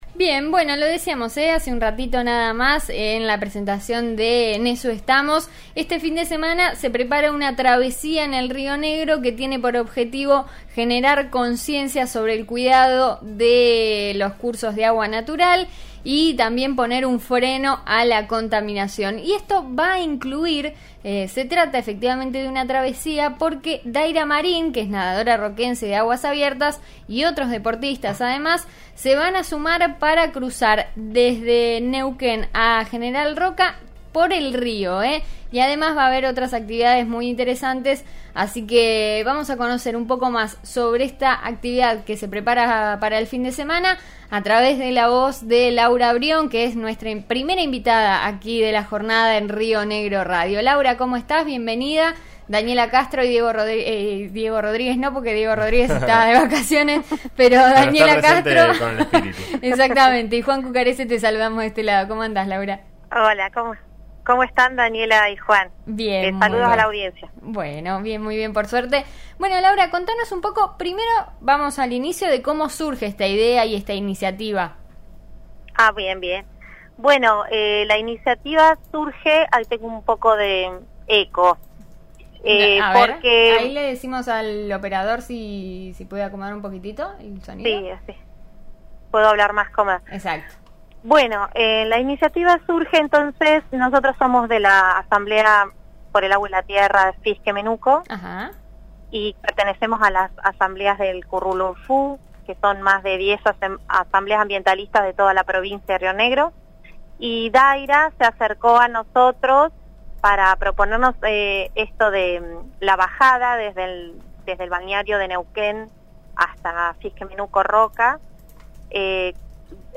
Al aire de 'En Eso Estamos' de RN RADIO, la Asamblea Socioambiental local invitó a sumarse.